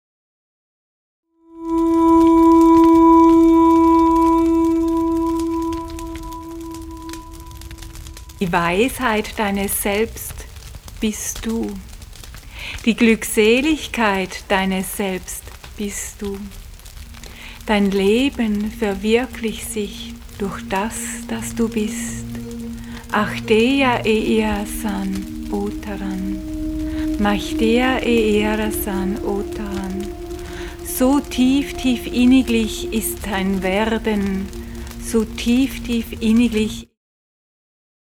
Meditative / Poesie
Momentum-Aufnahmen